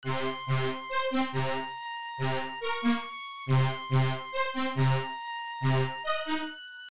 描述：这与《现金规则》中的黄铜是一样的，但是有弦。
标签： 140 bpm Crunk Loops Strings Loops 1.15 MB wav Key : Unknown
声道立体声